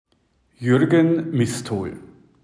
Wie spricht man eigentlich den Namen richtig aus,